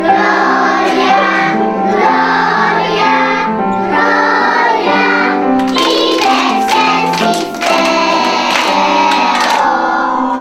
Po raz 13. odbył się w Przedszkolu nr 4 z Oddziałem Integracyjnym w Suwałkach przegląd kolęd i pastorałek.
W kolorowych przebraniach zaśpiewały bożonarodzeniowe piosenki.